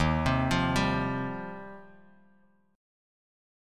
Listen to D#6add9 strummed